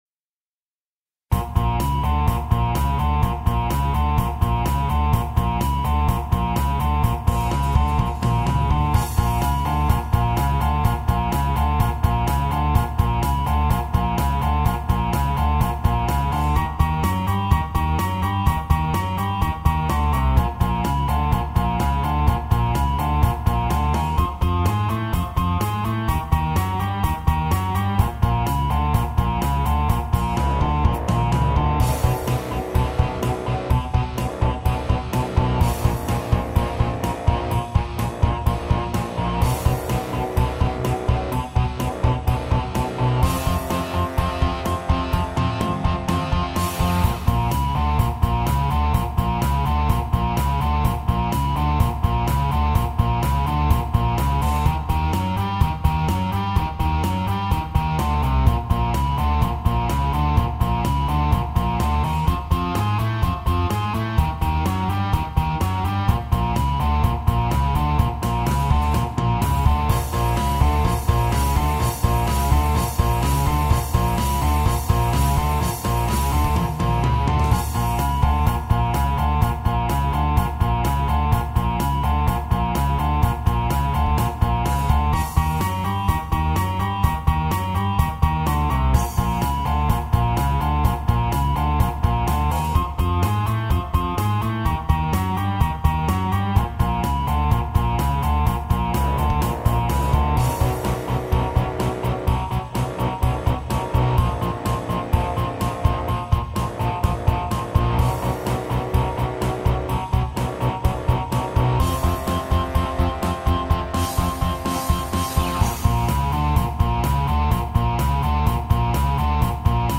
Home > Music > Rock > Running > Chasing > Restless